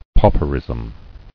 [pau·per·ism]